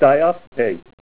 Help on Name Pronunciation: Name Pronunciation: Dioptase
Say DIOPTASE Help on Synonym: Synonym: ICSD 100077   PDF 33-487